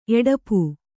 ♪ eḍapu